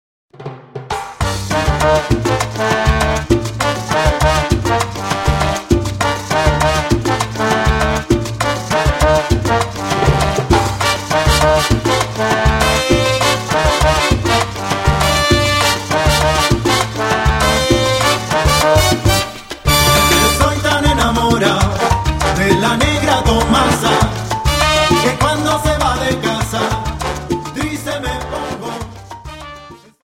Dance: Salsa 50